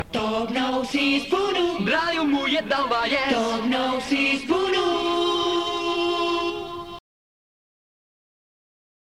Jingle del programa